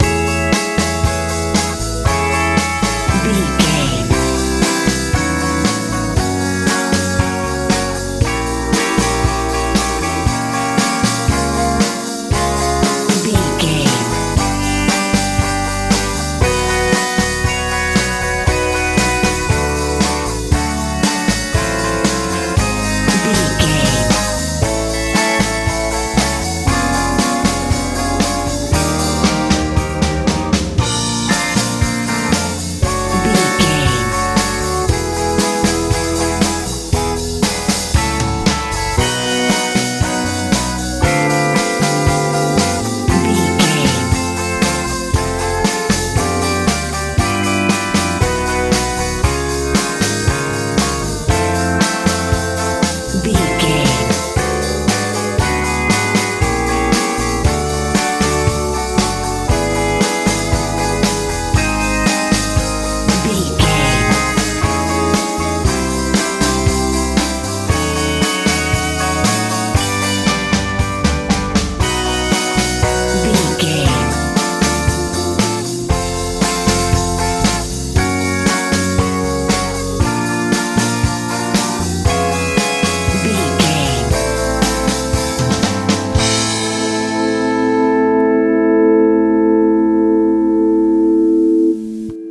60s pop
Ionian/Major
C♯
groovy
cool
electric guitar
bass guitar
electric piano
drums